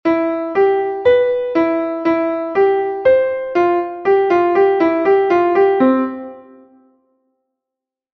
Melody example
melody_example.mp3